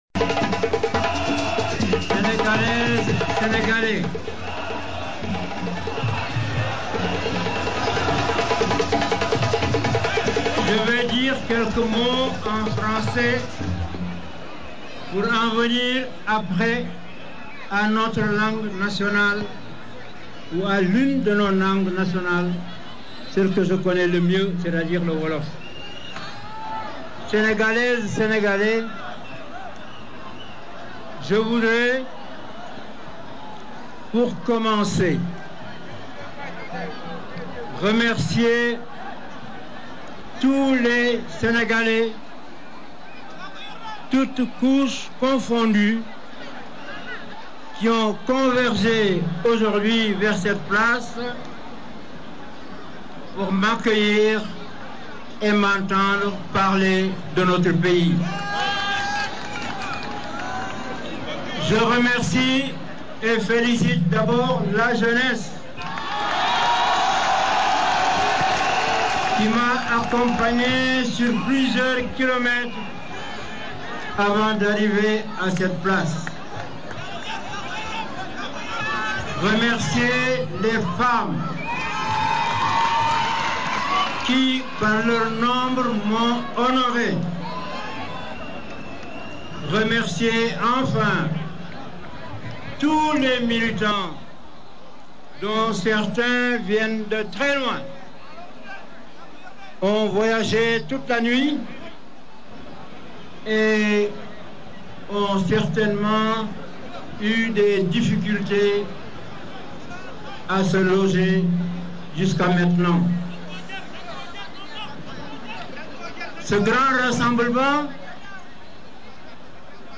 [ AUDIO ] MEETING DU PDS : LE DISCOURS DE ABDOULAYE WADE